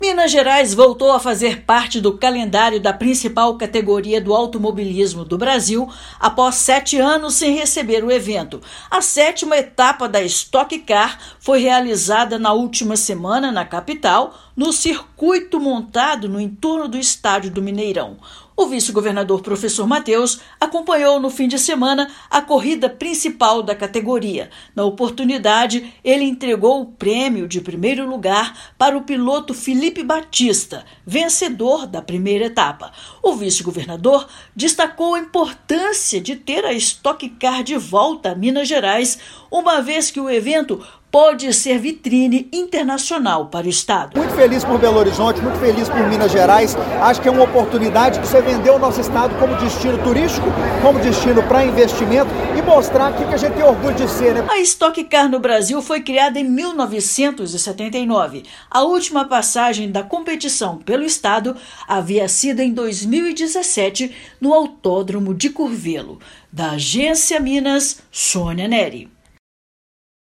Competição de automobilismo gerou empregos e oportunidades em passagem pela capital mineira. Ouça matéria de rádio.